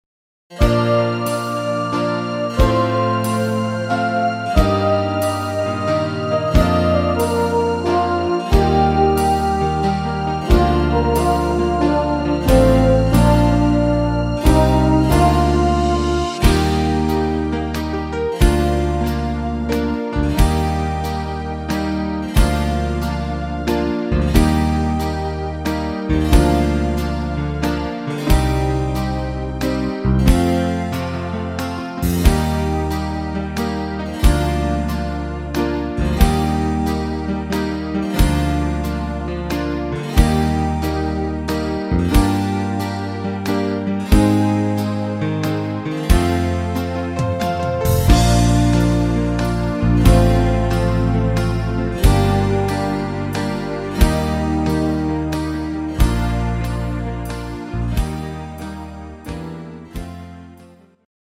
Slow-Waltz Version